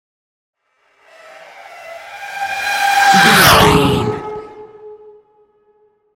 Sci fi vehicle pass by
Sound Effects
futuristic
high tech
intense
pass by